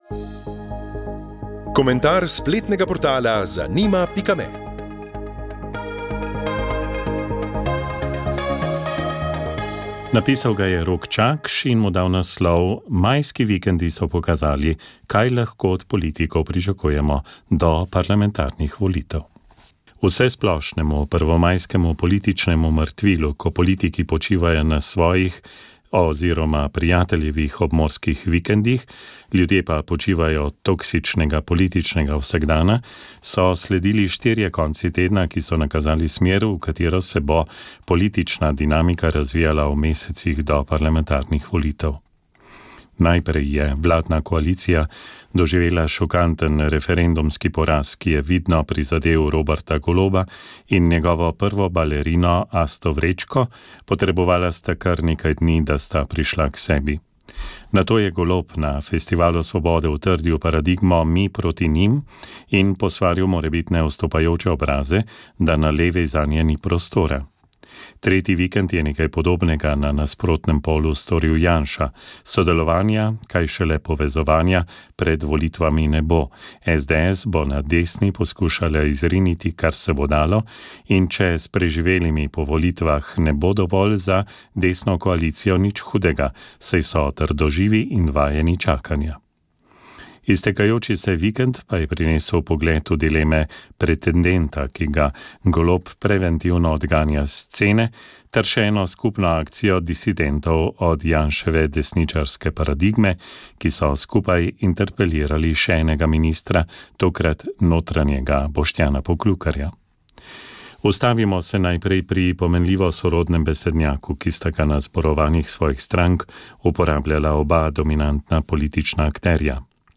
Radio Ognjišče knjiga zgodovina Radijski roman VEČ ...